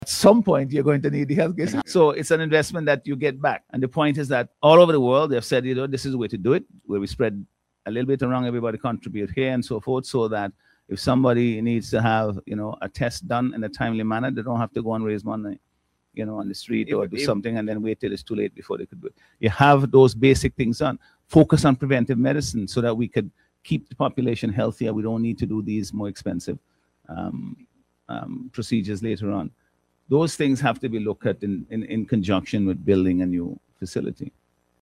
Speaking on radio recently, the Prime Minister said the proposed insurance system must work alongside the construction of the new hospital at Arnos Vale to strengthen access, affordability and sustainability in healthcare delivery.